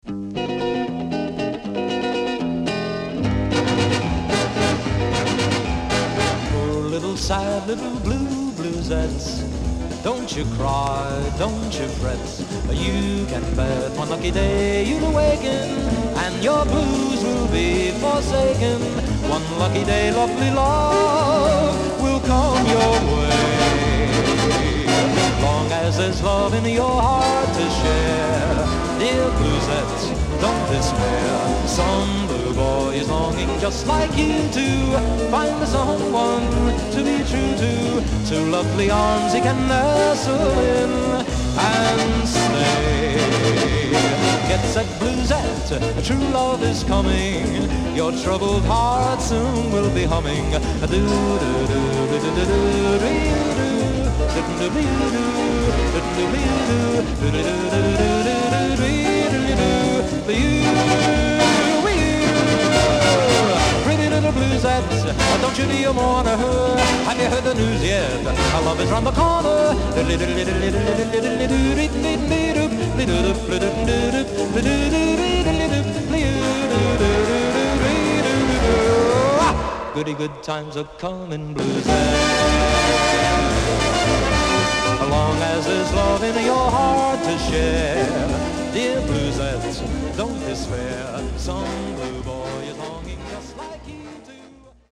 ダイナミックなオーケストレーション